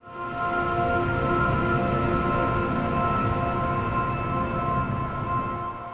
Samsung Galaxy S130 Startup.wav